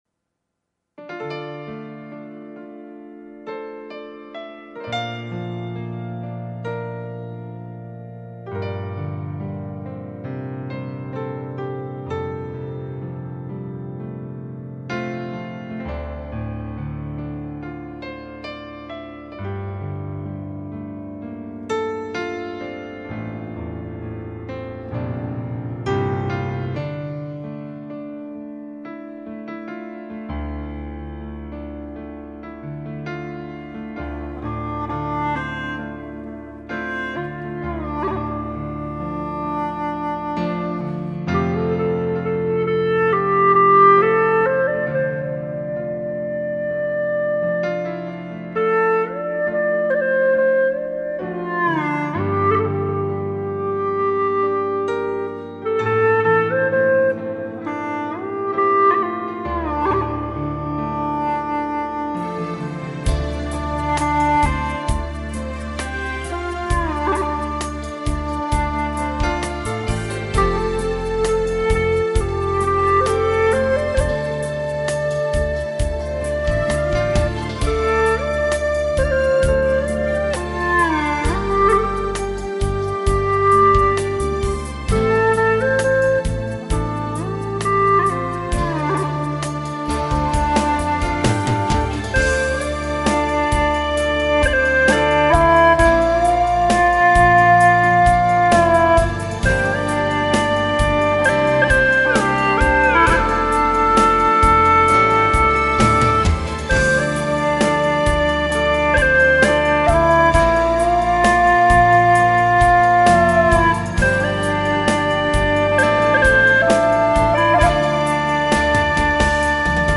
调式 : F 曲类 : 民族
虽然用七孔演奏有音域限制，但就是喜欢这首深情的作品，所以阻止不了我用七孔搞定它。